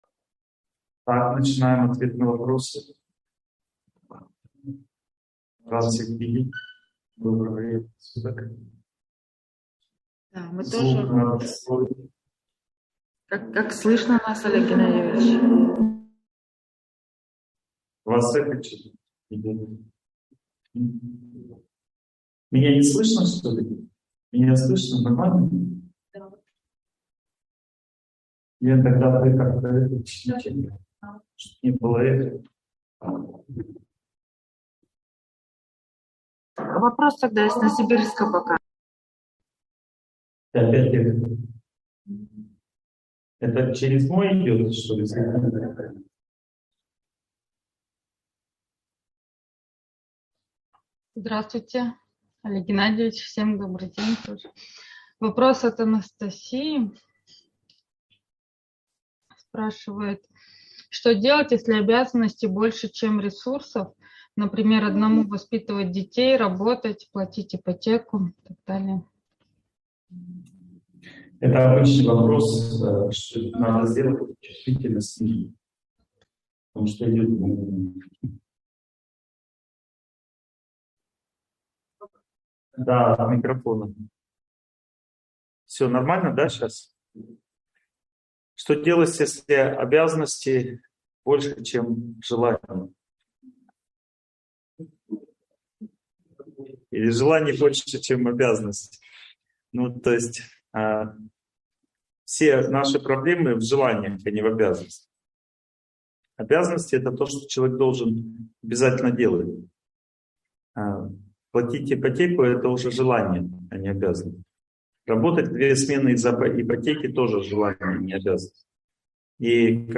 Обязанности (онлайн-семинар, 2022)